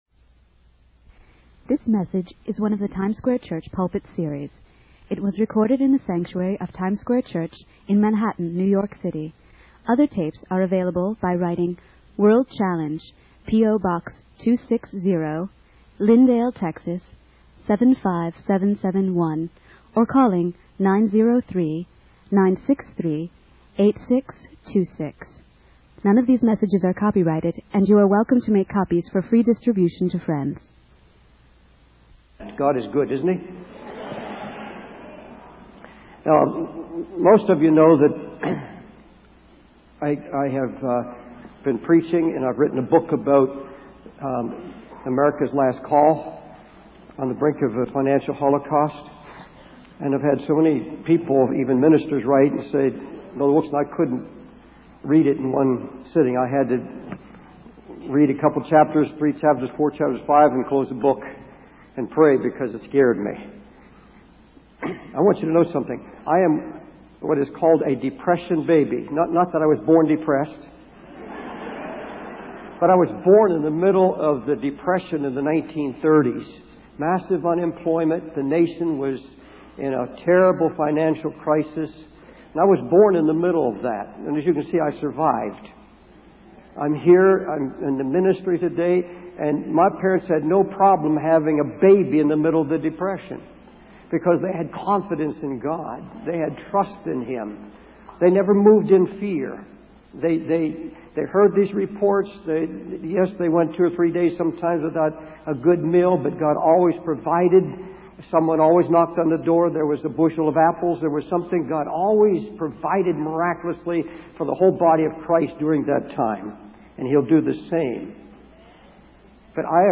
In this sermon, the speaker reflects on his experience growing up during the Great Depression and the lessons he learned about relying on God during times of trouble. He references Isaiah 26:20, which speaks of God punishing the world for its iniquity and instructs His people to hide themselves until the storm of God's judgment passes.